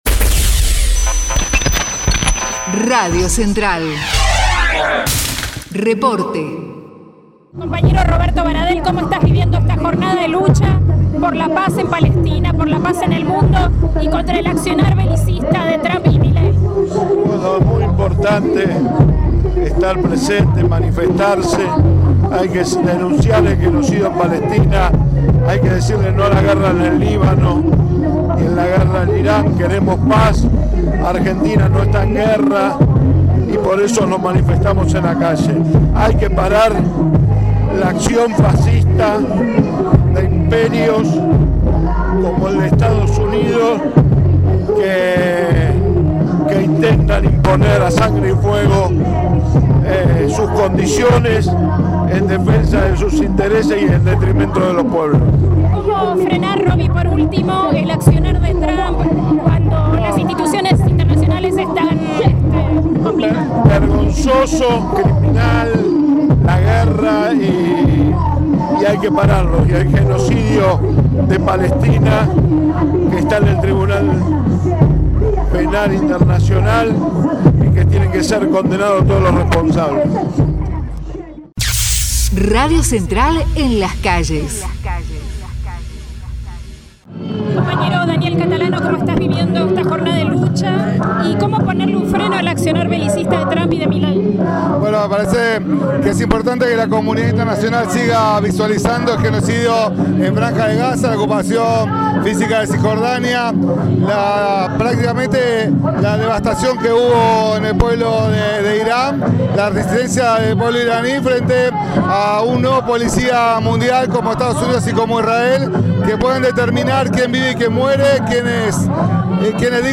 MARCHA EN DEFENSA DE PALESTINA: Testimonios CTA
2026_marcha_en_defensa_de_palestina.mp3